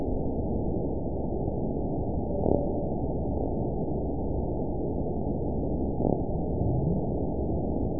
event 922908 date 05/03/25 time 07:08:44 GMT (1 month, 2 weeks ago) score 5.74 location TSS-AB06 detected by nrw target species NRW annotations +NRW Spectrogram: Frequency (kHz) vs. Time (s) audio not available .wav